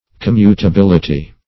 Commutability \Com*mu`ta*bil"i*ty\, n.